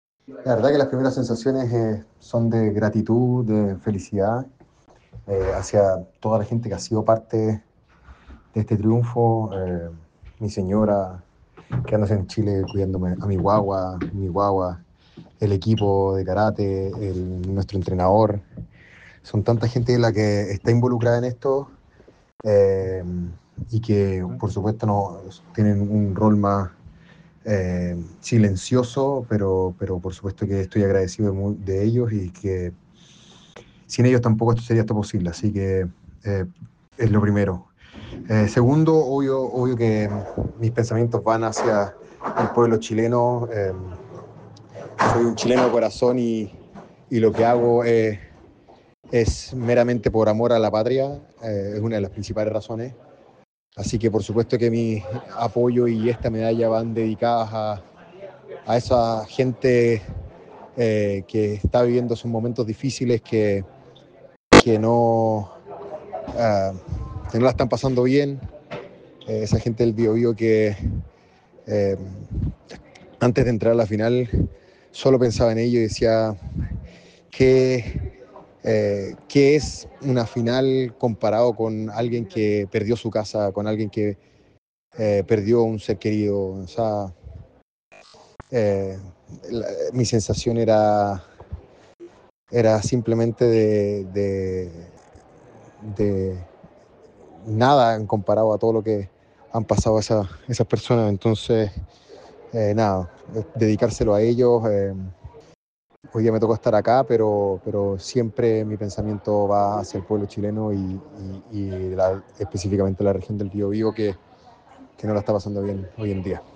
En conversación con ADN Deportes, el peleador explicó las dificultades que debió afrontar para quedarse con el título.